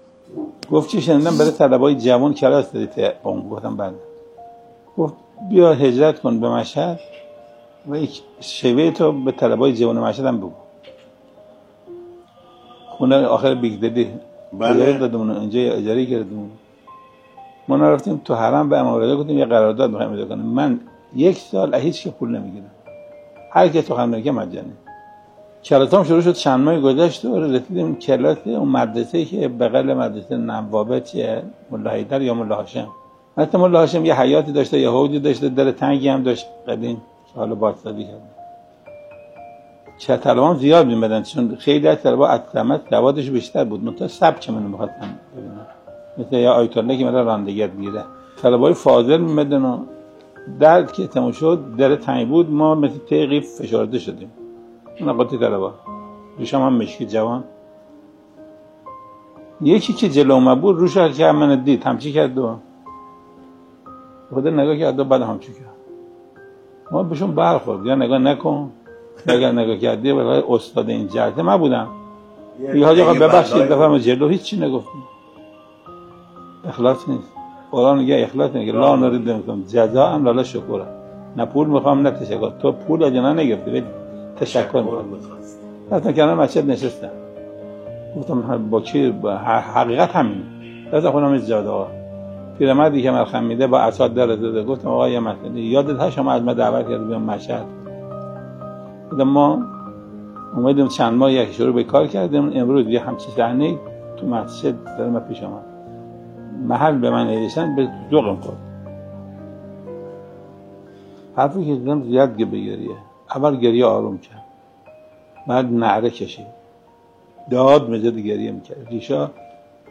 صوت ســـخنرانی: